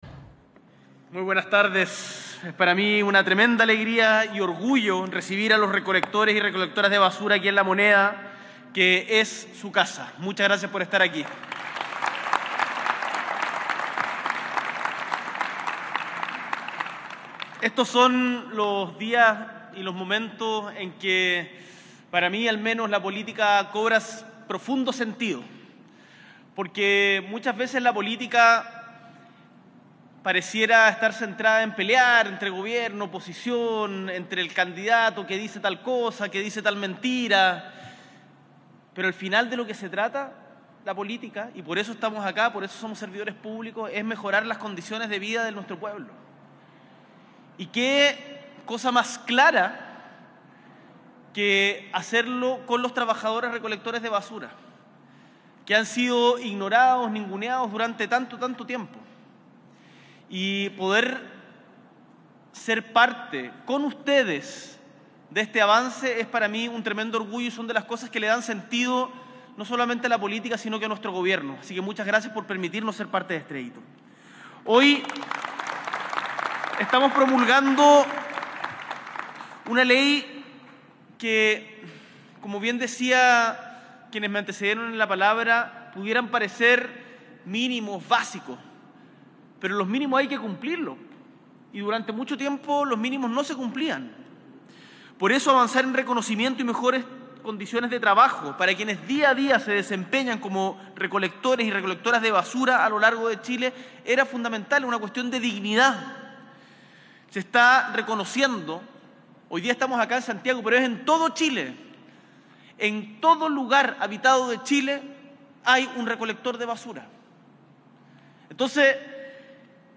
S.E. el Presidente de la República, Gabriel Boric Font, encabeza la promulgación de la Ley que reconoce la función de los Recolectores de Residuos Domiciliarios
Discurso